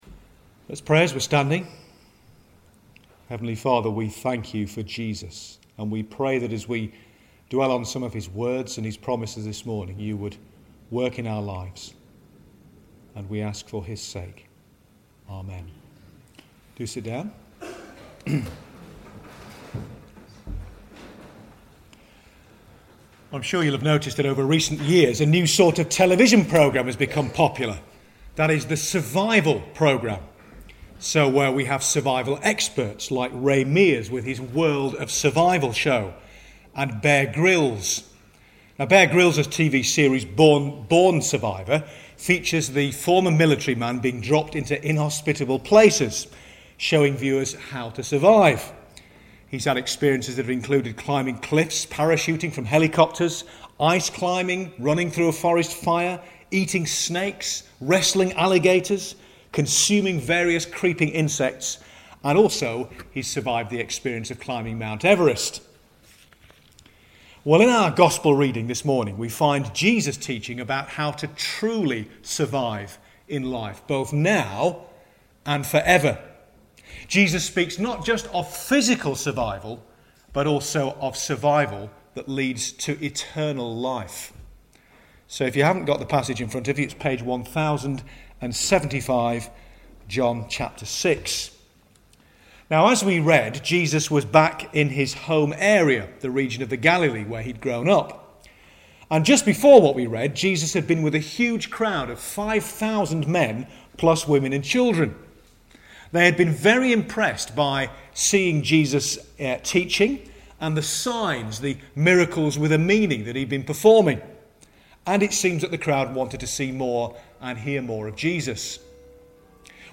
“Summer Survival” Holiday Club Service Jesus – The Bread of Life – John 6:22-35